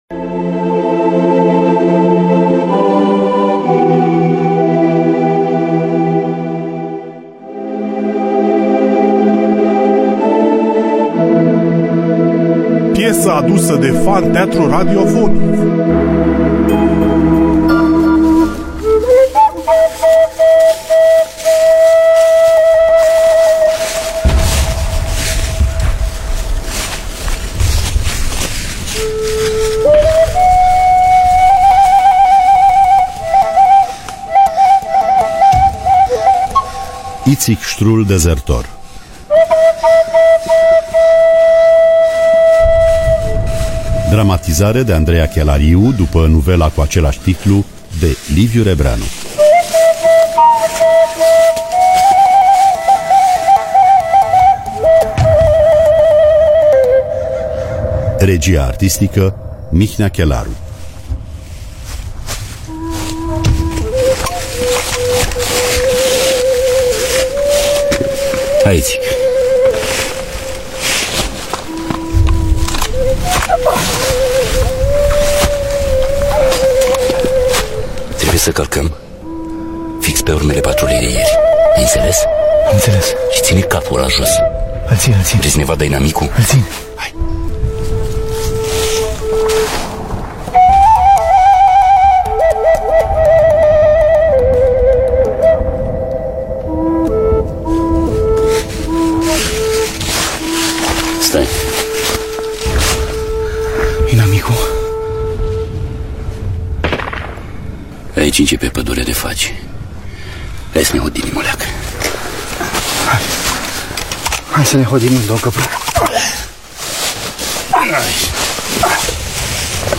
Dramatizarea radiofonică
fluier